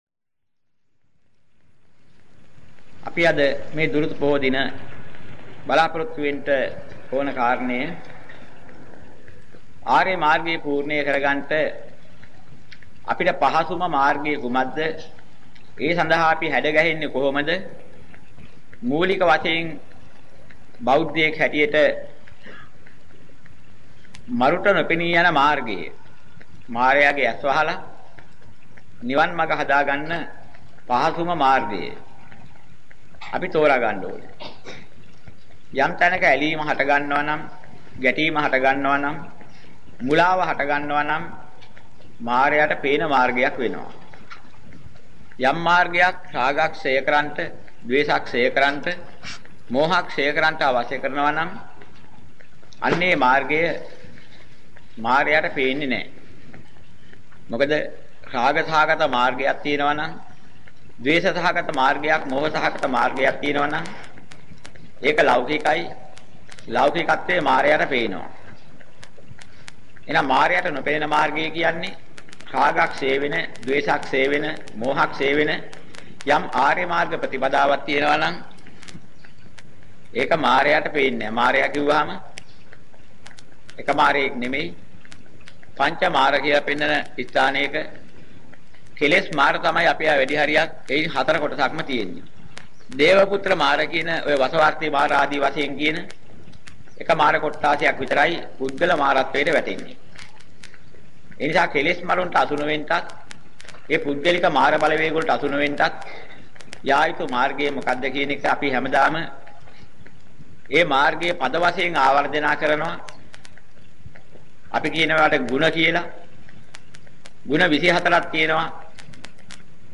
මෙම දේශනාවේ අඩංගු ගාථා හෝ සූත්‍ර කොටස්